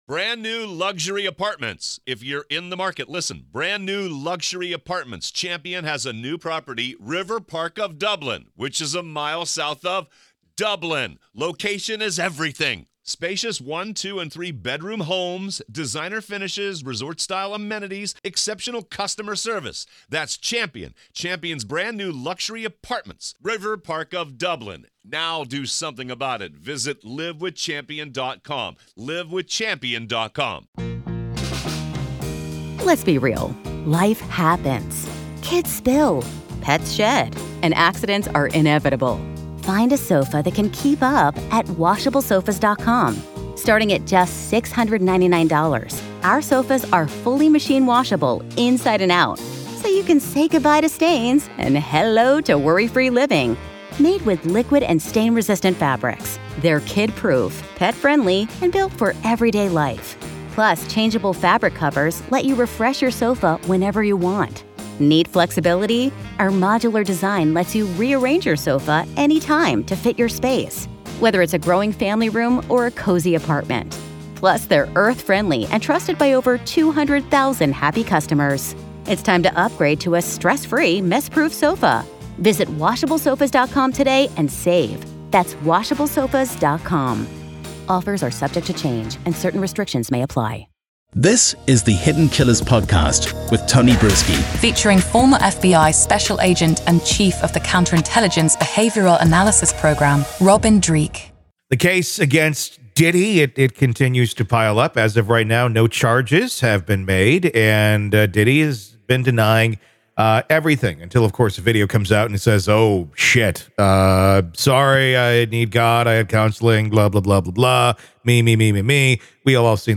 He emphasizes that tracking the flow of money from Diddy to those involved in the hit on Tupac could be crucial. The conversation underscores the complexities of the case and the broader implications for the music industry if a substantial connection to Diddy is proven.